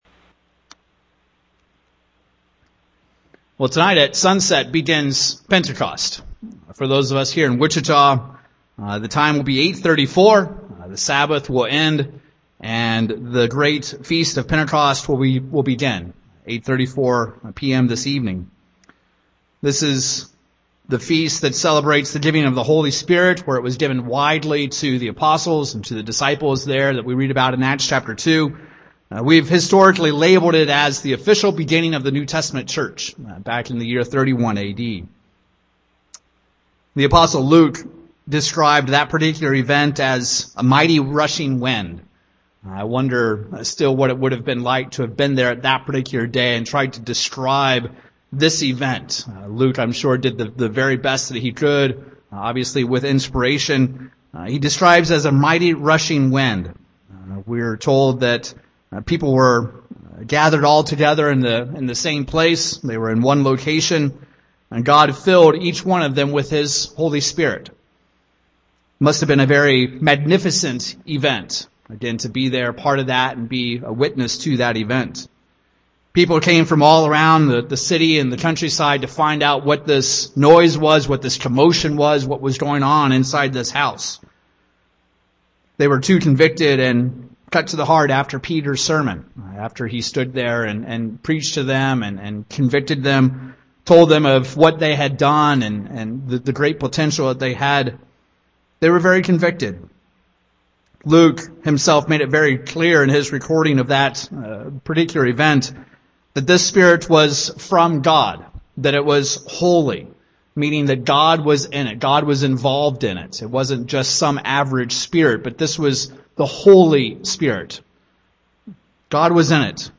Given in Wichita, KS
UCG Sermon Studying the bible?